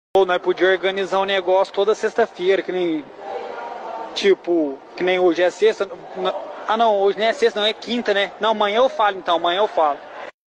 hoje nao e sexta nao e quinta ne Meme Sound Effect
hoje nao e sexta nao e quinta ne.mp3